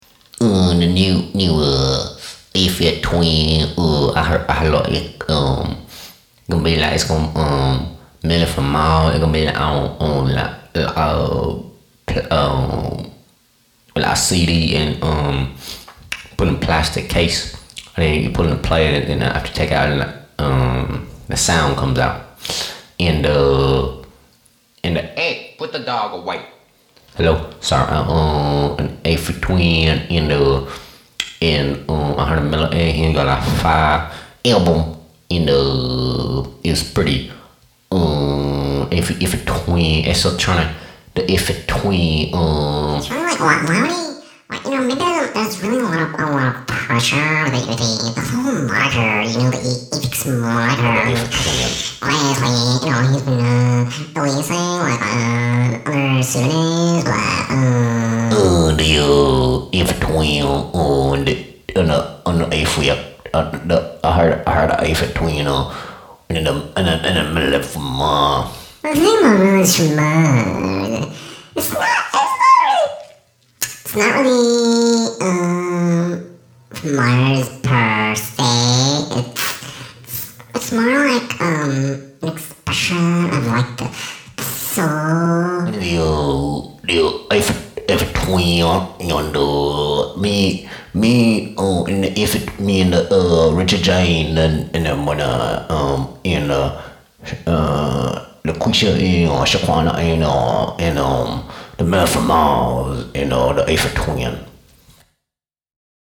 The real voice is the high one, btw.